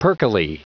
Prononciation du mot perkily en anglais (fichier audio)
Prononciation du mot : perkily